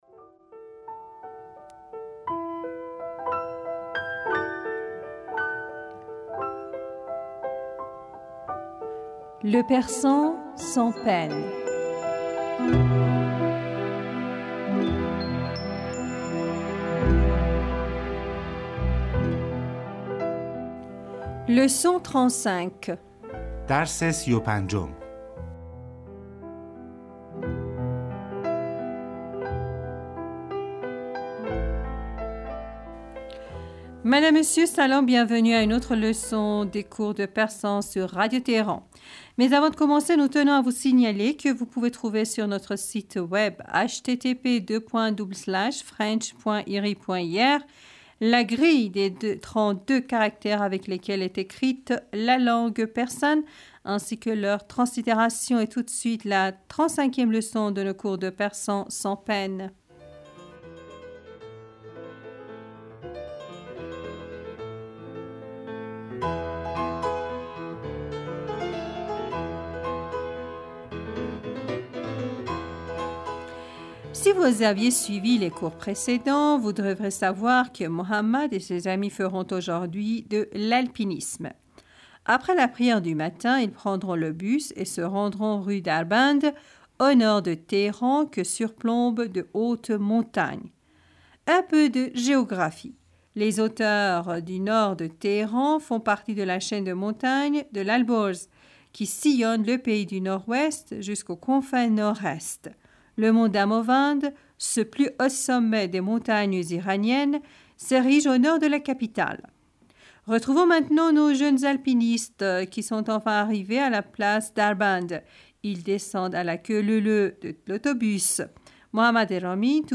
Bienvenus à une autre leçon des cours de persan sur Radio Téhéran.